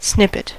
snippet: Wikimedia Commons US English Pronunciations
En-us-snippet.WAV